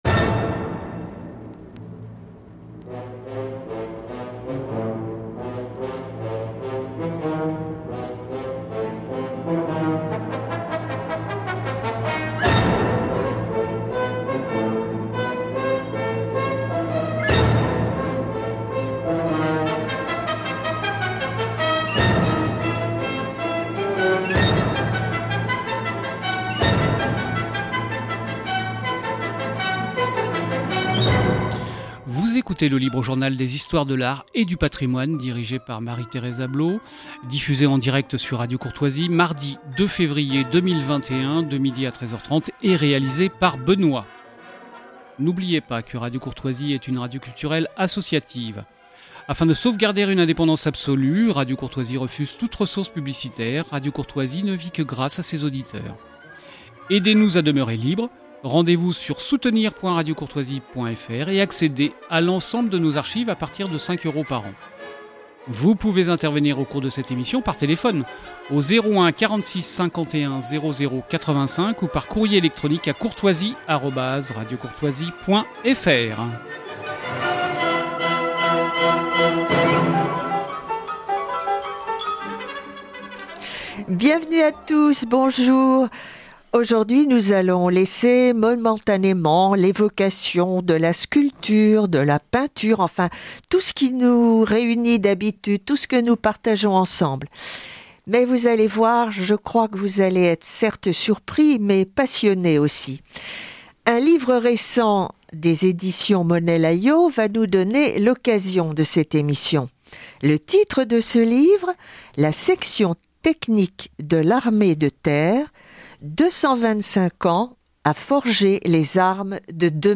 Écouter l’émission de RADIO COURTOISIE